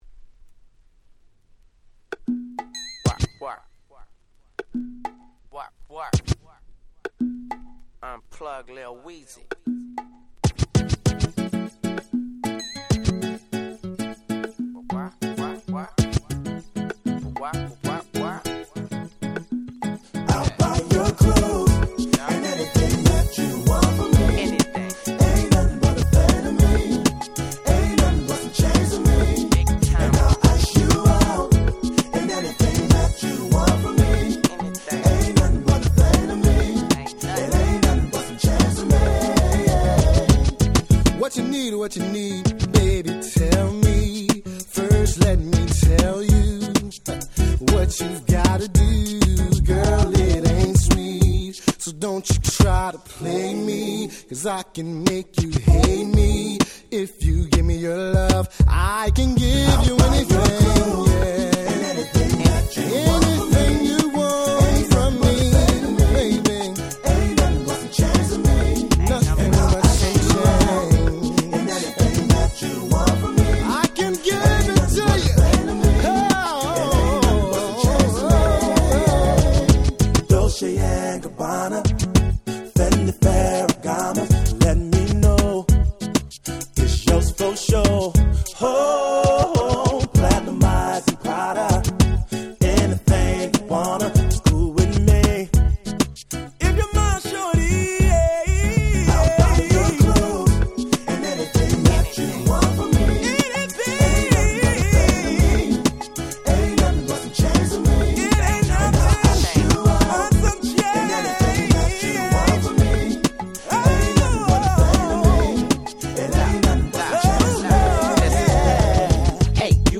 01' Very Nice R&B / Hip Hop Soul !!